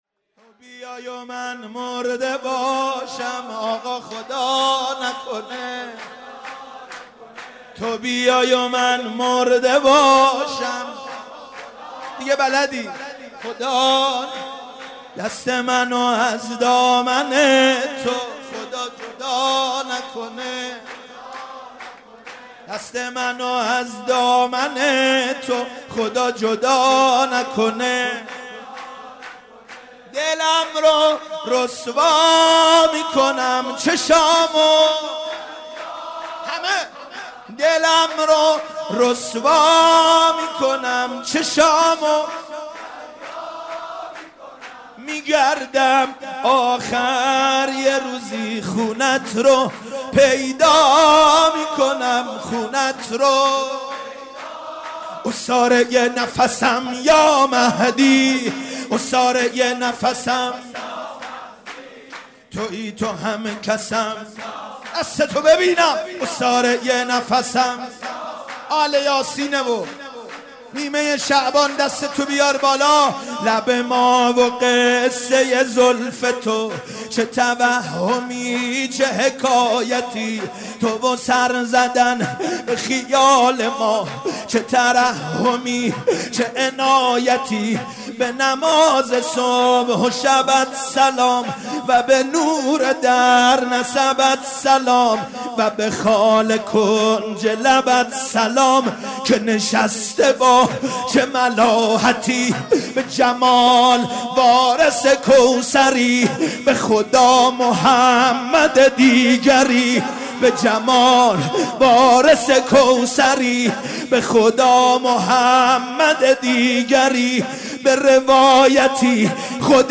شب میلاد حضرت علی اکبر(ع) / هیئت آل یا سین قم
سرود
مدح